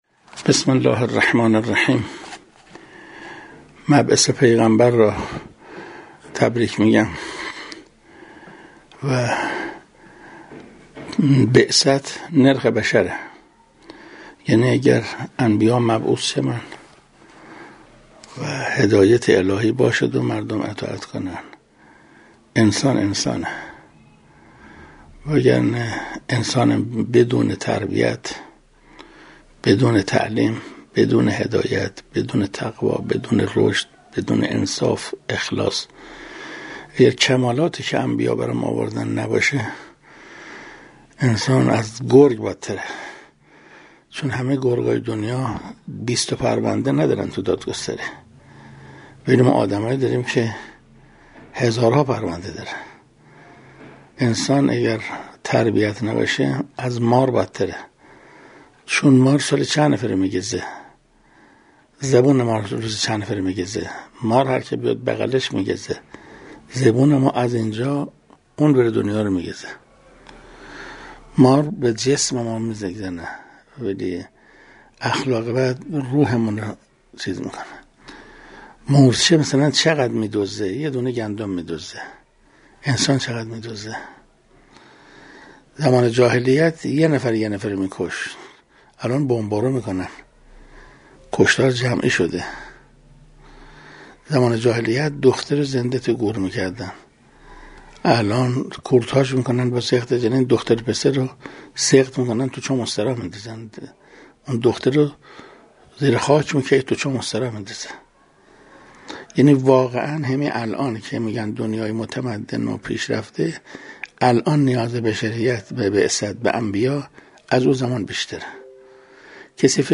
حجت‌الاسلام والمسلمین محسن قرائتی، مفسر قرآن کریم و رئیس ستاد اقامه نماز کشور در یکی از سخنرانی‌های خود، بعثت را نرخ بشر عنوان کرد و گفت: نیاز انسان به بعثت امروز بیش از زمان جاهلیت است.